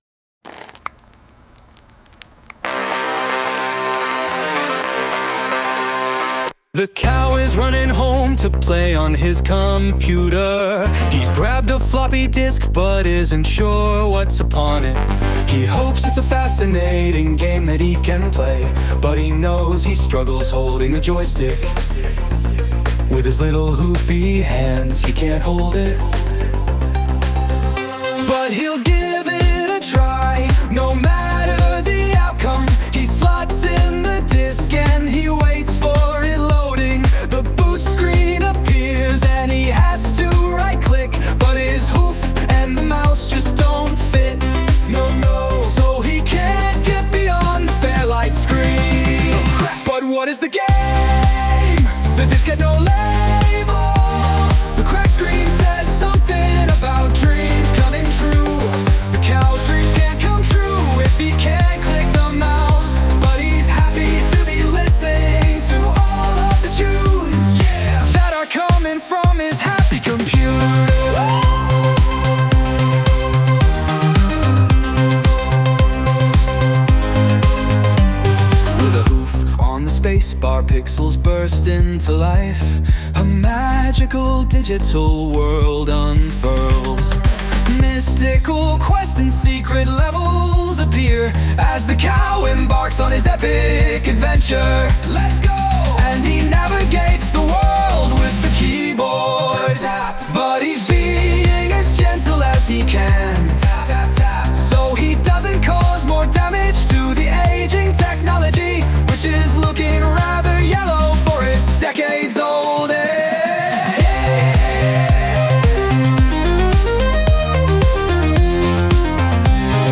Lyrics : By me
Sound Imported : Generous Decision